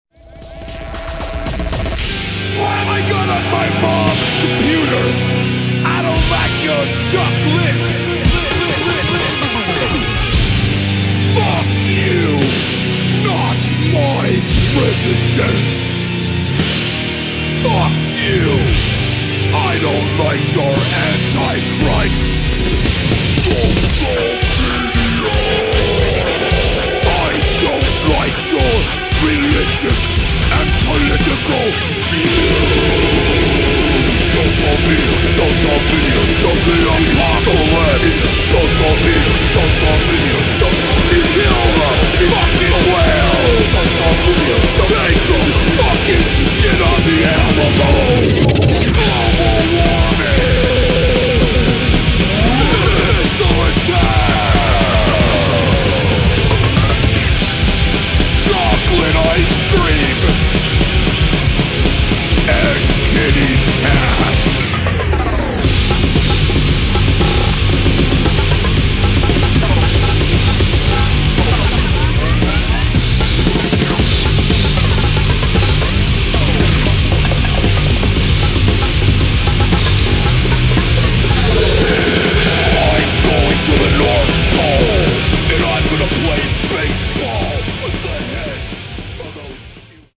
[ HARCORE ]
ブレイクコア/ハードコア/メタル